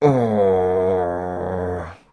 Звуки злости, ворчання
Ворчливый мужик